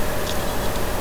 HDD na NB - divne zvuky
Akoby sa stale inicializovali hlavicky alebo neviem co? robi to niekedy viackrat za sebou v jednej minute, niekedy po nejakej pauze 2 minut a je to dost znepokojujuci zvuk.
je to to cvakanie, ten hluk je len sum okolia - ventilatory - je to zosilene, aby to mikrofon zachytil.